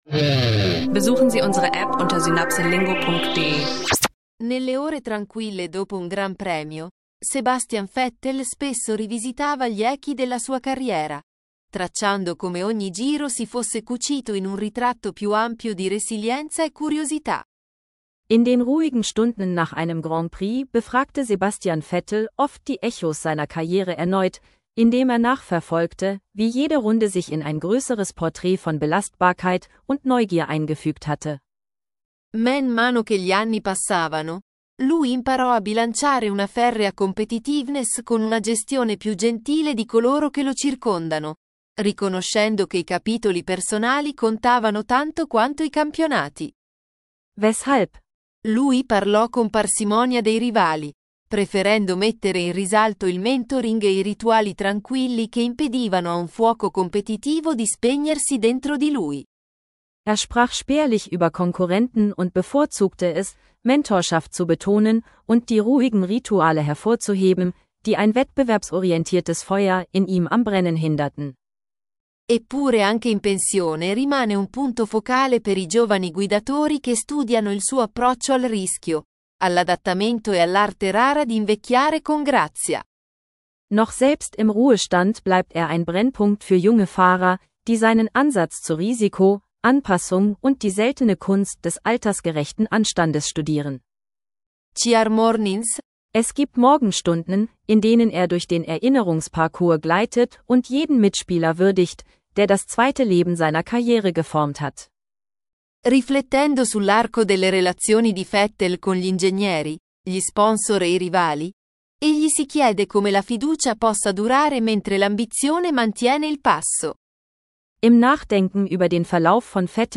Ein reflektierender Blick auf Sebastians Vettel Karriere – Italienisch lernen mit einer fesselnden, zweisprachigen Erzählung.